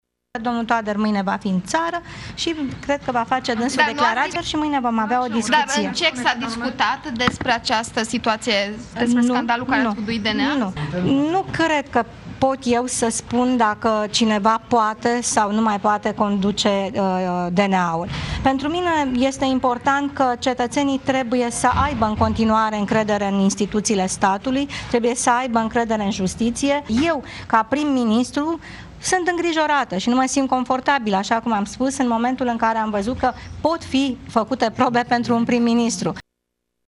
Așa a declarat Viorica Dăncilă la ieșirea de la sedința Comitetului Executiv al PSD.